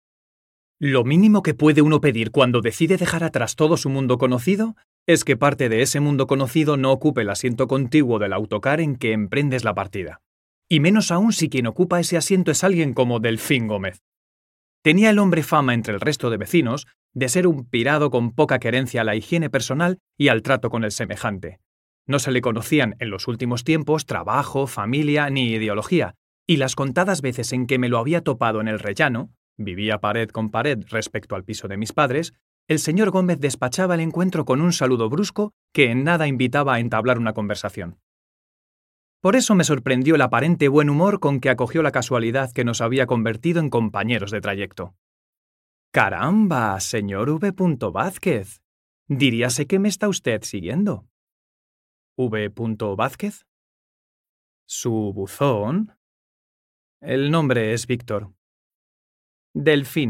Así suenan algunas de nuestras voces profesionales para vídeos:
Demo-Audiolibro-mp3cut.net_.mp3